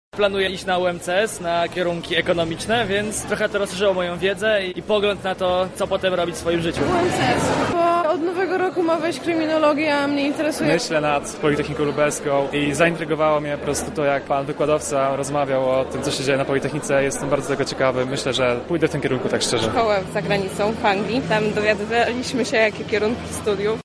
Na salonie obecny był nasz reporter.